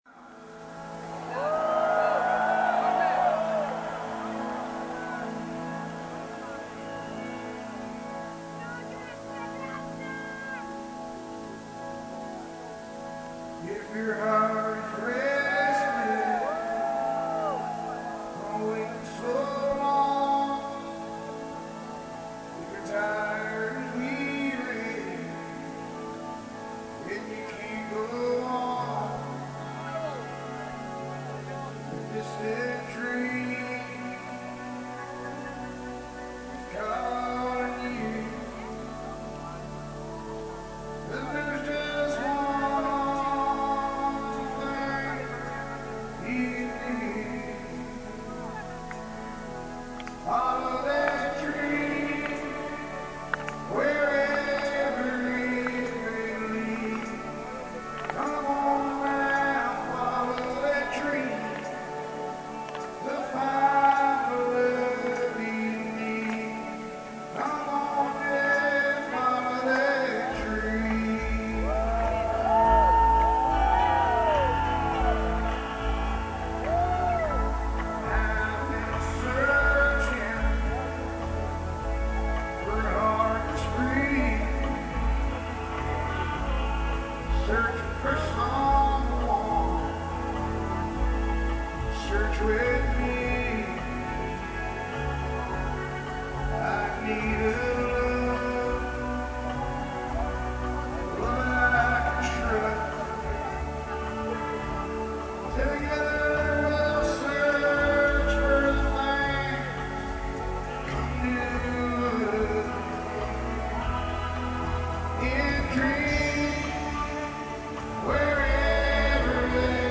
28 Giugno 2003 - Milano - Stadio S.Siro